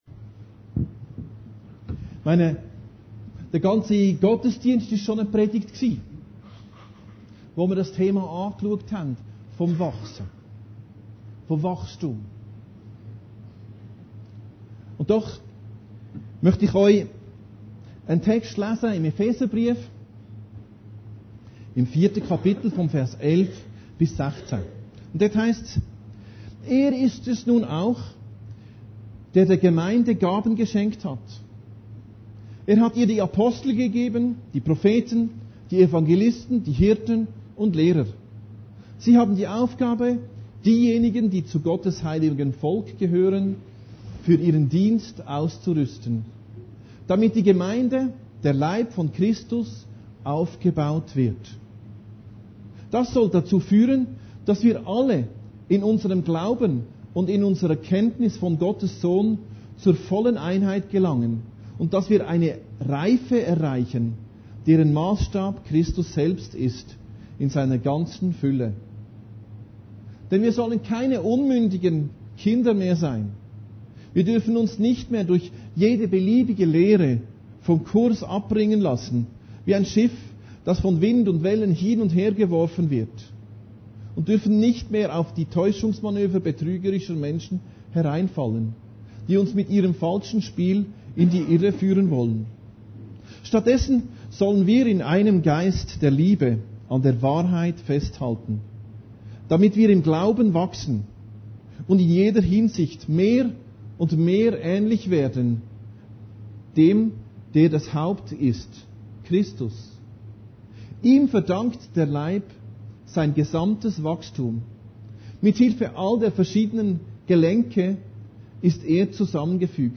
Predigten Heilsarmee Aargau Süd – Aufruf zur Vertiefung des Glaubens